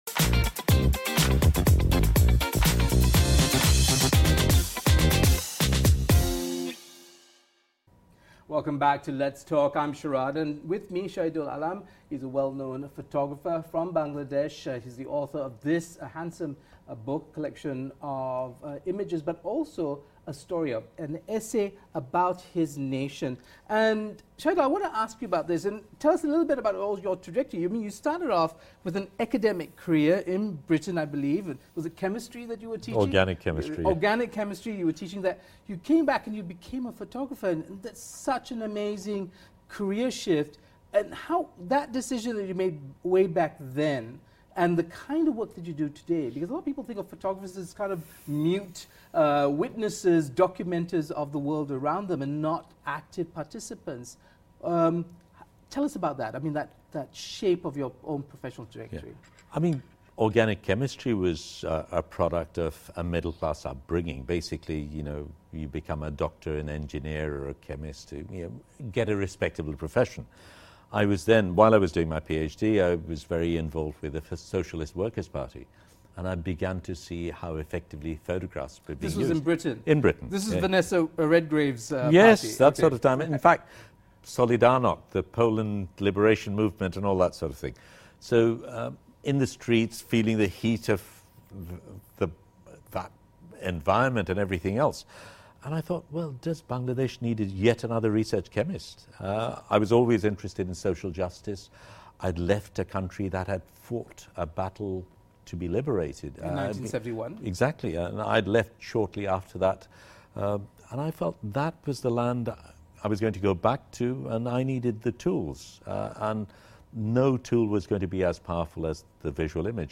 speaks to world-renowned Bangladeshi photographer